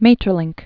(mātər-lĭngk, mĕtər-, mä-tĕr-lăɴ), Count Maurice 1862-1949.